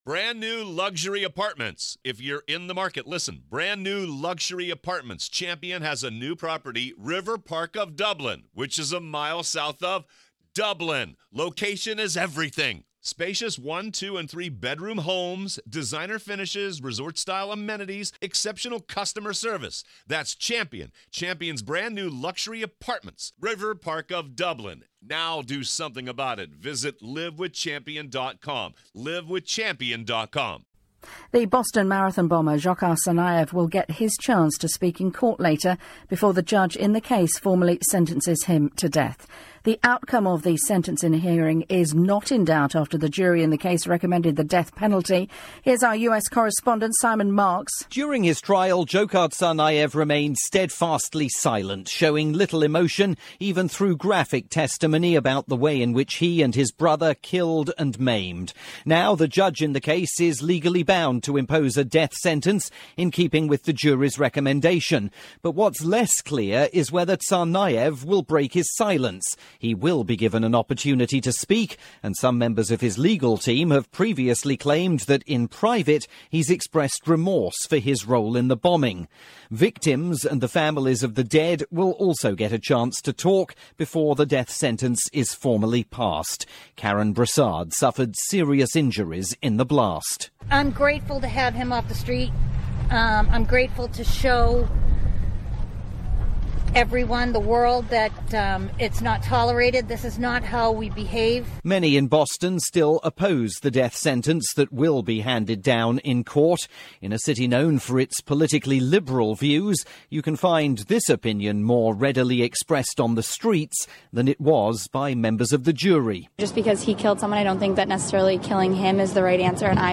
Boston Marathon bomber Dzhokar Tsarnaev will formally receive his death sentence in Boston today. This report aired on LBC's Morning News.